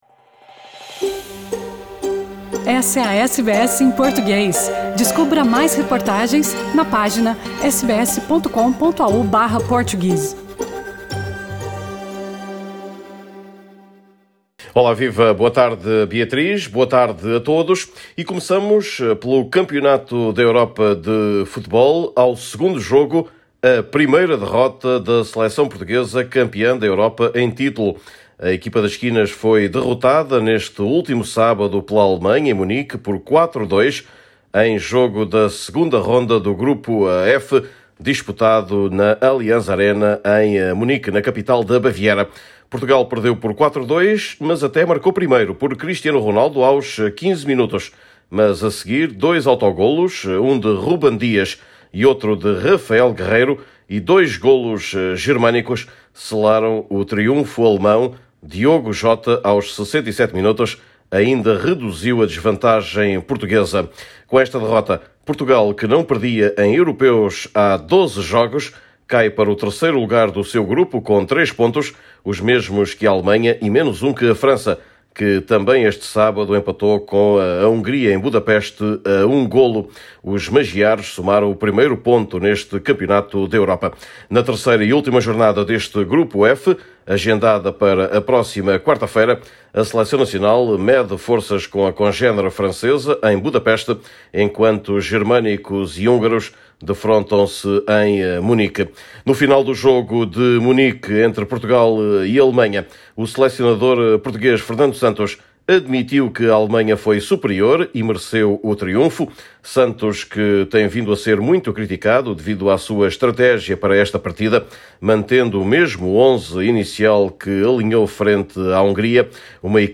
Neste boletim, vamos conhecer ainda os nomeados lusos para o prémio de melhor jogador jovem ou falar de mercado de transferências.